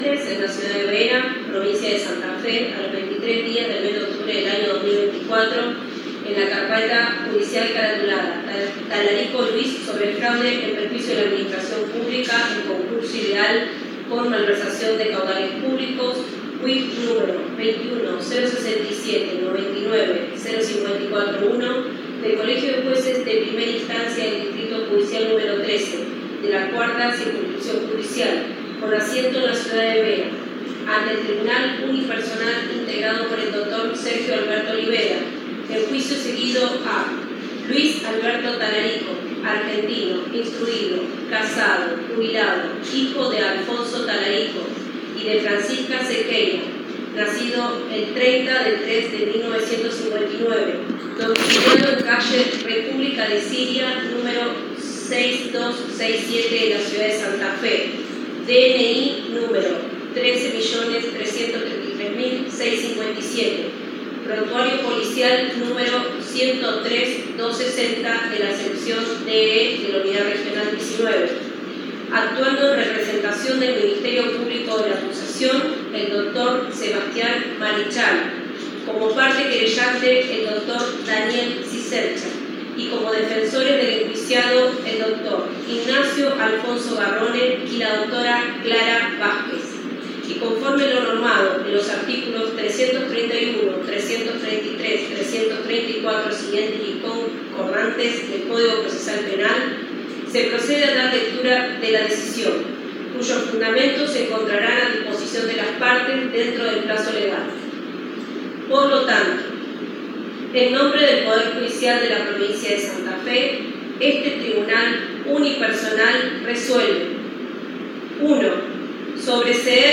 La última audiencia se dio con cada una de las partes conectados vías zoom.
Lectura del fallo